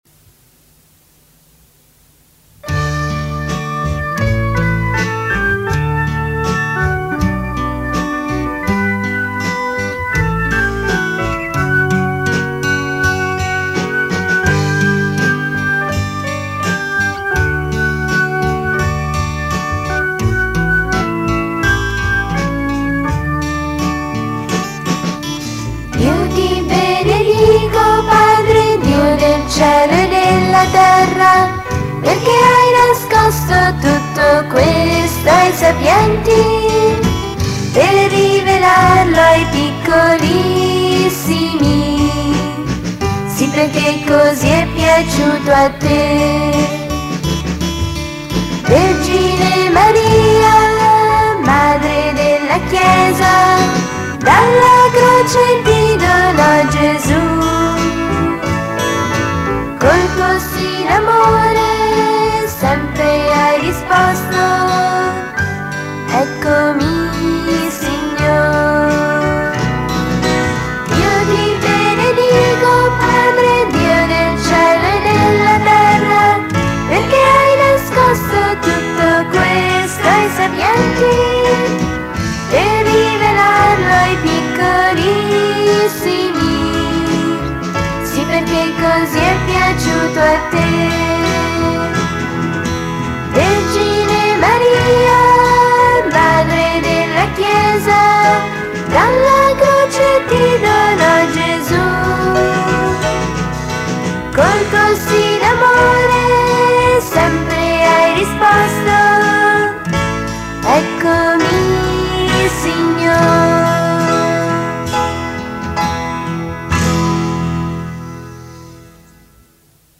Canto per Rosario e Parola di Dio: Io ti benedico, Padre.